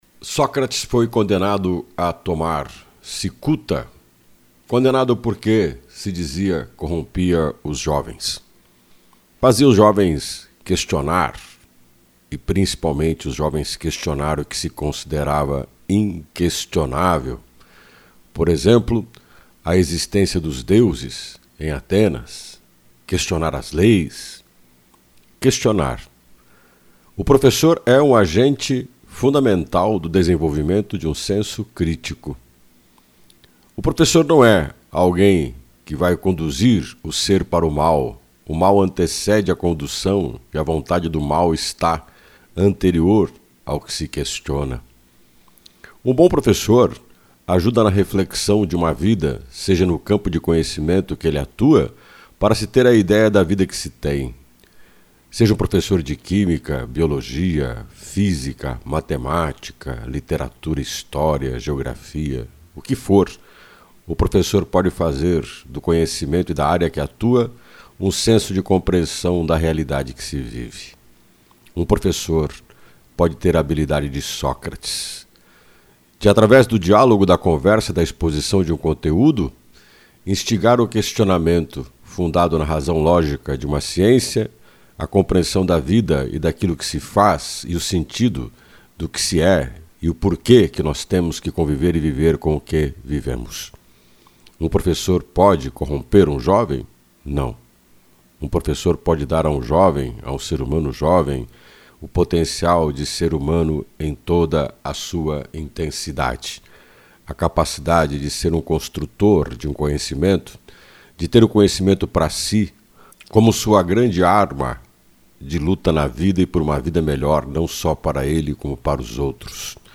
Opinião